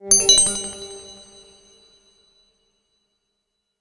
06384 magic collect ding
bonus collect ding game magic ring sfx sound sound effect free sound royalty free Sound Effects